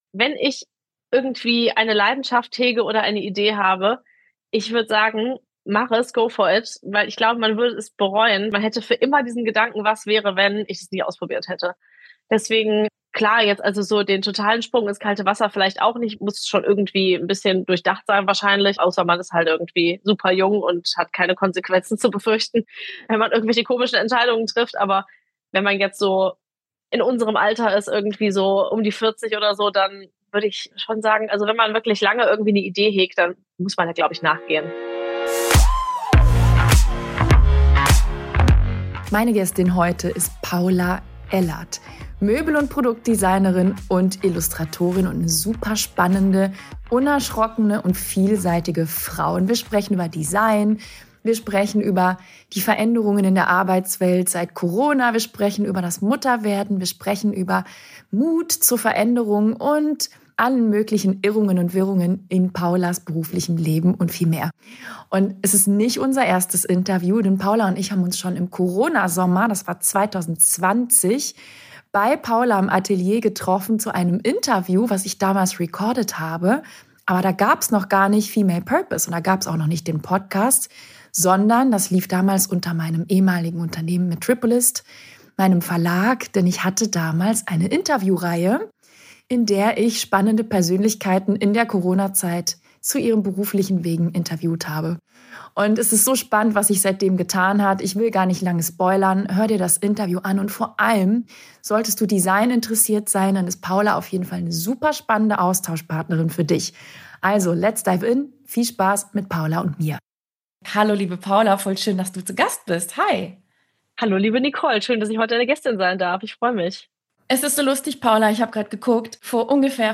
#171 | Go for it – folge deiner Leidenschaft! Interview